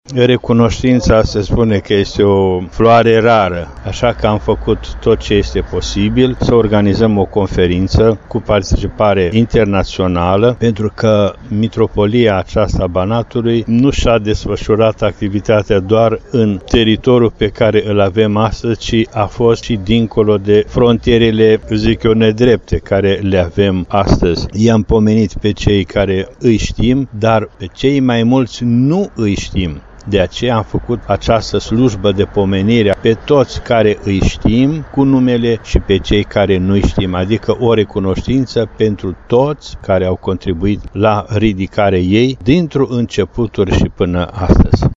Cu acest prilej, astăzi, în Biserica de lemn, monument istoric, din incinta Centrului Eparhial a fost oficiată o slujbă de pomenire a ctitorilor și binefăcătorilor Mitropoliei, iar în Sala de festivități se desfășoară un simpozion internațional pe tema Istorie, Cultură și Spiritualitate în Banat.
Cuvântul de întâmpinare a fost rostit de Inalt Prea Sfinția Sa, Ioan, Mitropolitul Banatului.